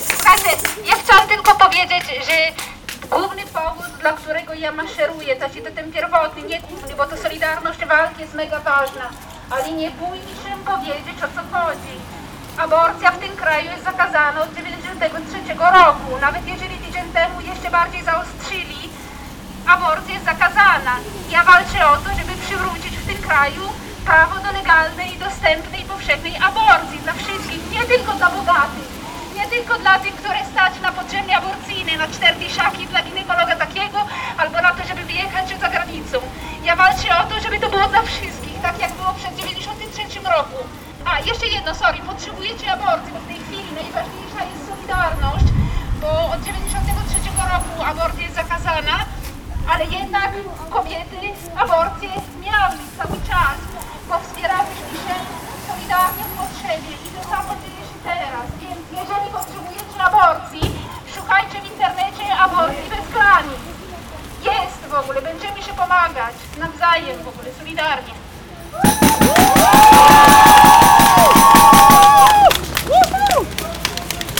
Liderki Ogólnopolskiego Strajku Kobiet podczas wczorajszej (1 listopada) konferencji prasowej przedstawiły szeroką listę postulatów, ale podczas protestu padły słowa przywołujące sedno tej akcji – umożliwienie dostępu do aborcji osobom potrzebującym takiej pomocy.